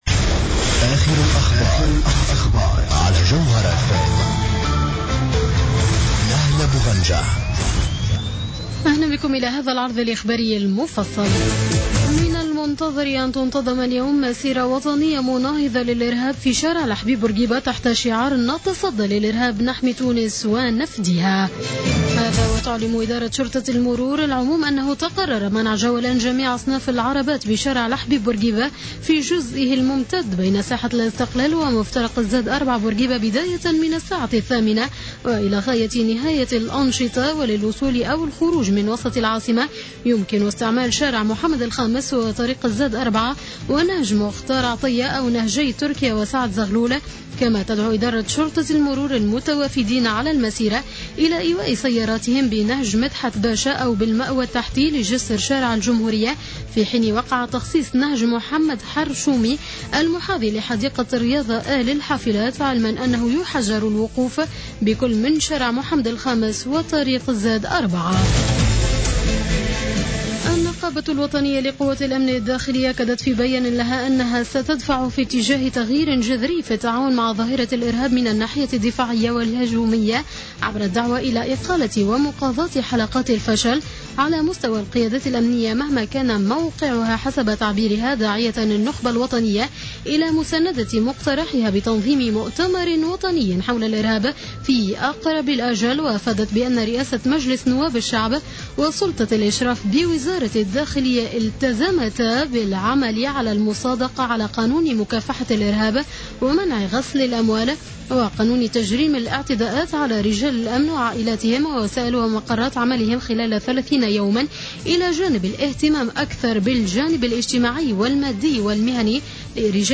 نشرة أخبار منتصف الليل ليوم السبت 21 فيفري 2015